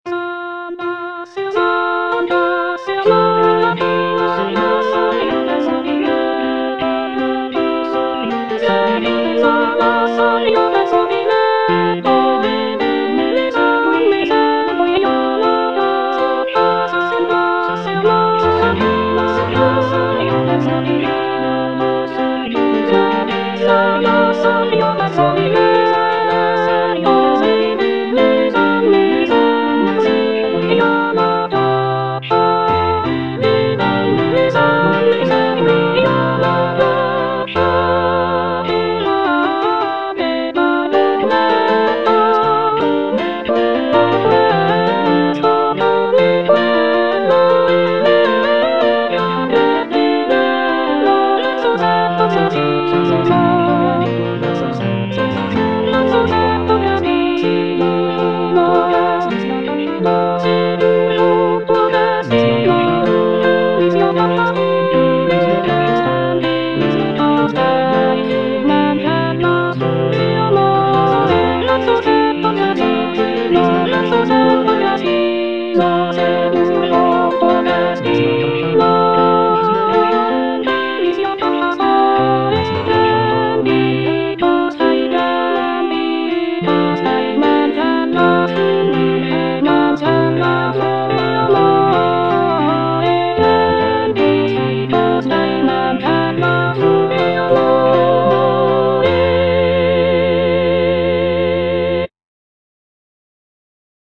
C. MONTEVERDI - S'ANDASSE AMOR A CACCIA Soprano II (Emphasised voice and other voices) Ads stop:
"S'andasse Amor a caccia" is a madrigal composed by Claudio Monteverdi, an Italian composer from the late Renaissance period.